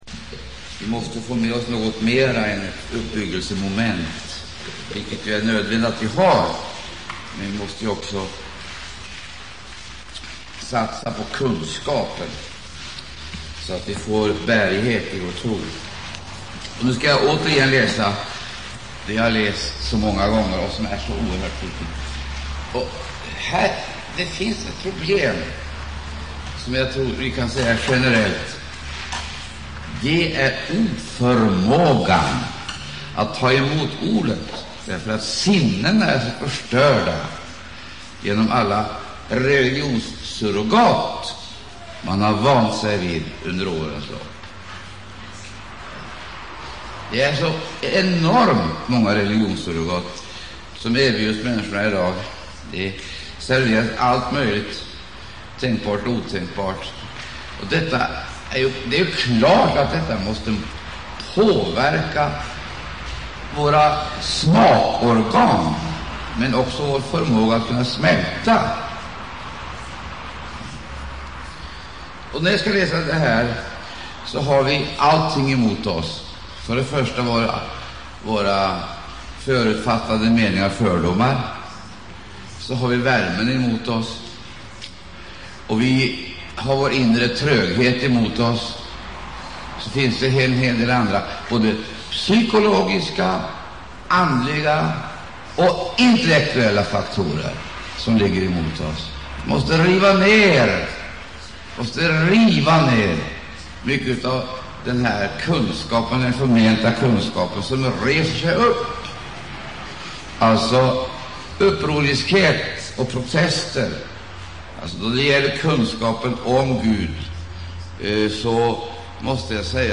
Sjätte delen i en undervisningsserie
inspelad under Maranataförsamlingens sommarkonferens 1994.